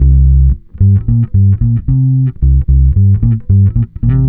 Track 15 - Bass 04.wav